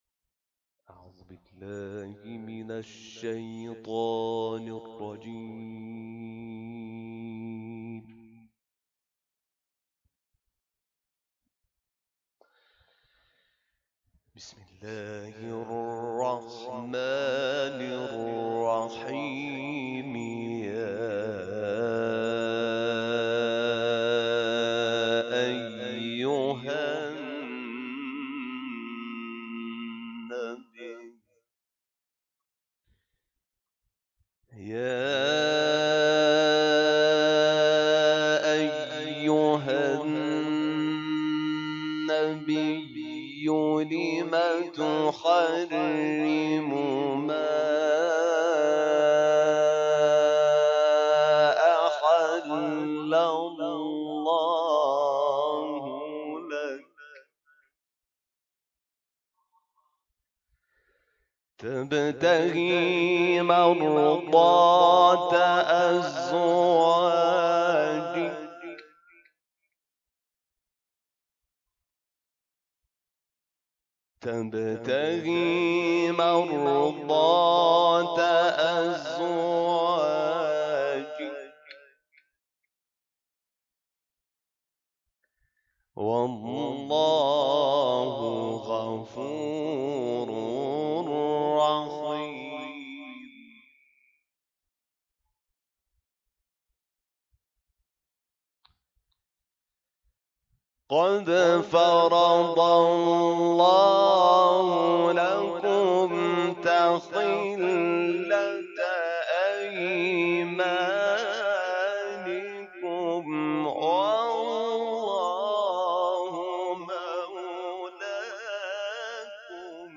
گروه جلسات و محافل: محفل انس با قرآن کریم با حضور قاری بین‌المللی حمید شاکرنژاد در موسسه قرانی دارالتحفیظ شهرستان ساوه برگزار شد.